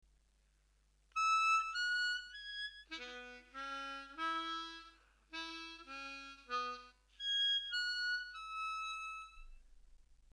For now though, we’re using a regular Richter tuned 10 hole diatonic harmonica.
We’re in cross harp (second position) on an A harmonica – key of E major.
Looped Country Scale
For musical correctitude, here are the remaining safe notes that loop off the top end of the harp and onto the bottom end.